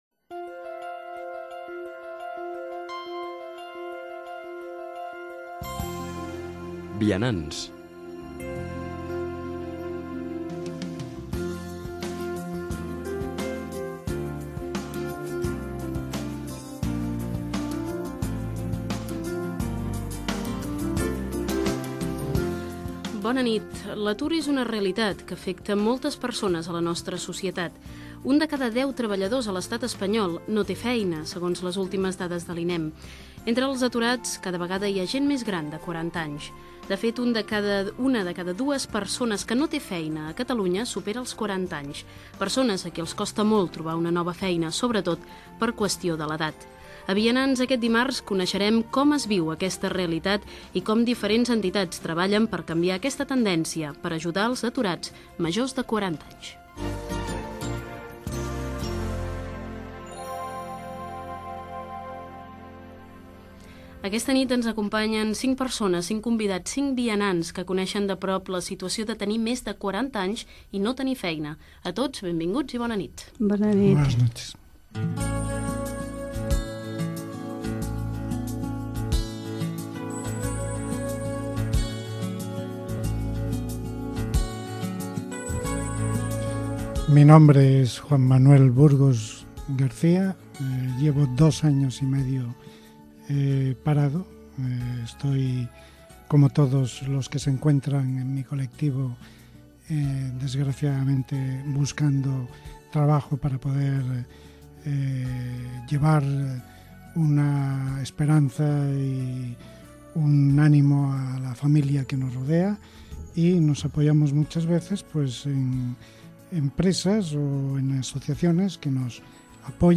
Careta del programa, dades sobre l'atur de persones grans, presentació dels invitats per parlar de l'atur en majors de 40 anys, explicacions d'un apersona que està en aquella situació
FM